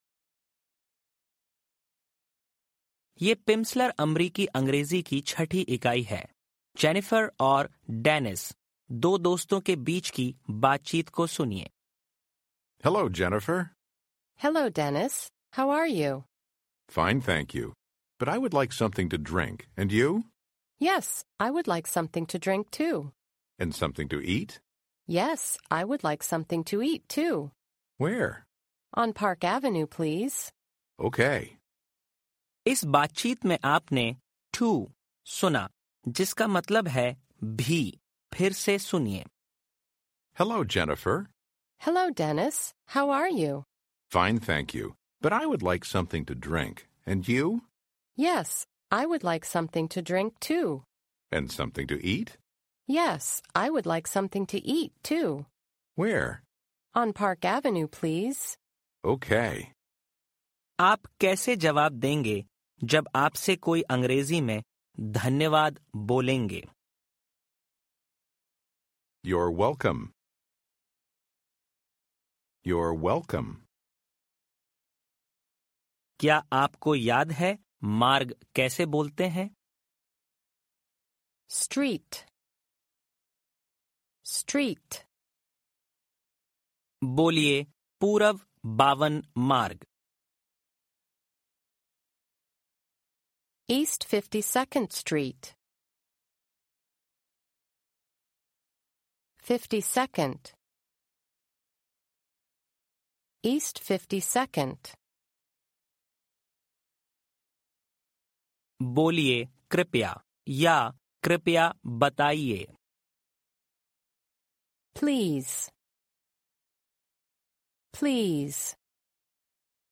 Each lesson provides 30 minutes of spoken language practice, with an introductory conversation, and new vocabulary and structures.
This course teaches Standard American English as spoken in the US.